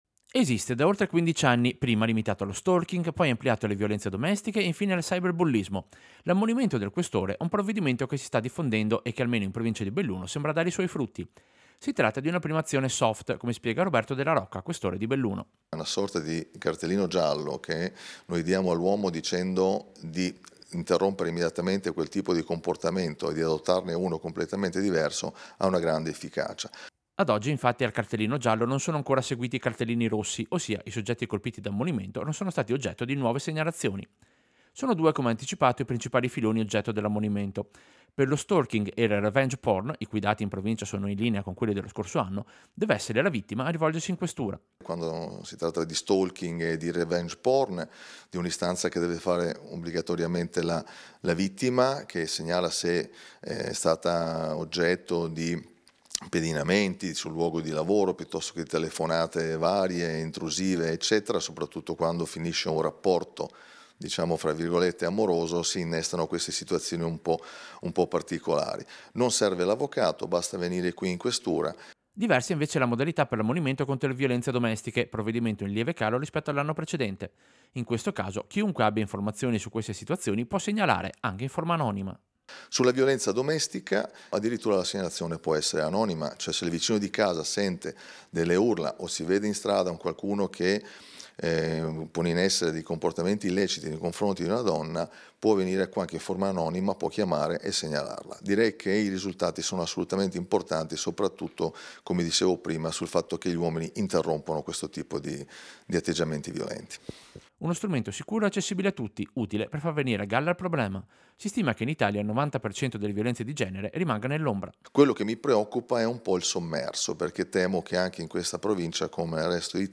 Servizio-Questore-situazione-ammonimenti.mp3